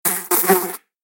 دانلود صدای حشره 15 از ساعد نیوز با لینک مستقیم و کیفیت بالا
جلوه های صوتی